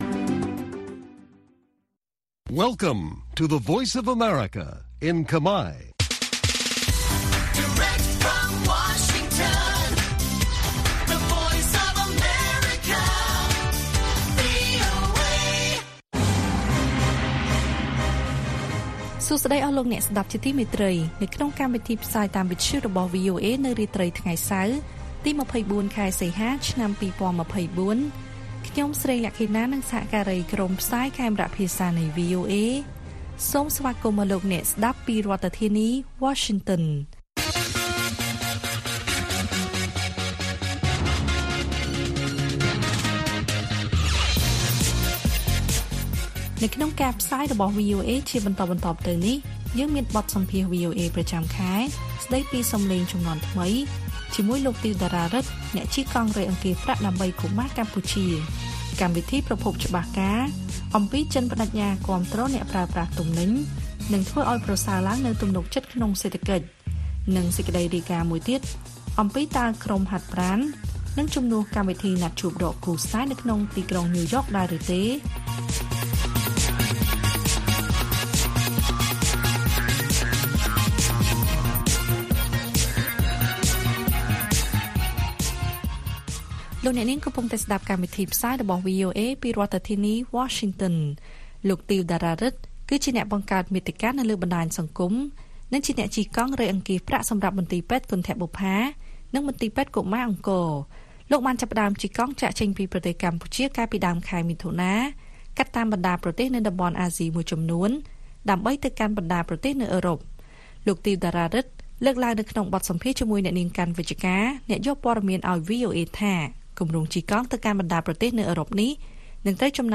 ព័ត៌មានពេលរាត្រី ២៤ សីហា៖ បទសម្ភាសន៍ប្រចាំខែស្តីពី «សំឡេងជំនាន់ថ្មី»